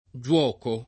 giuoco [ JU0 ko ]